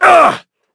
Shakmeh-Vox_Attack5.wav